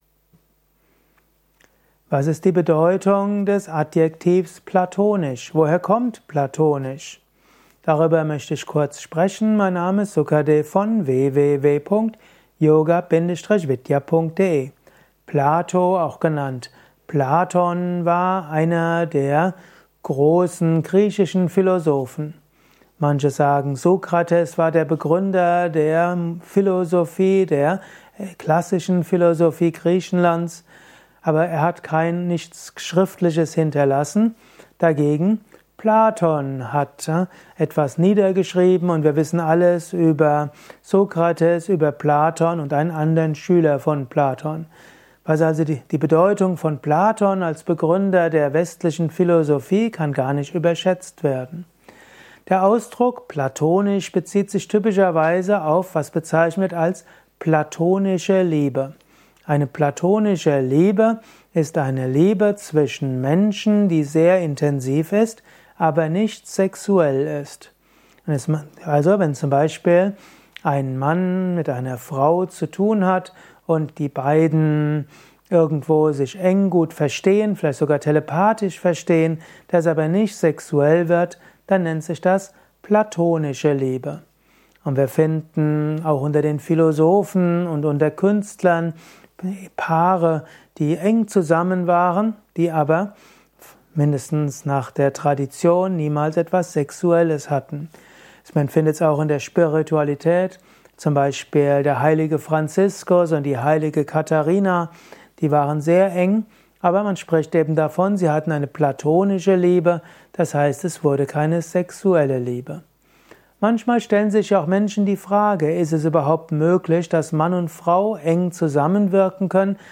Dieser Vortrag ist Teil des Liebe Podcasts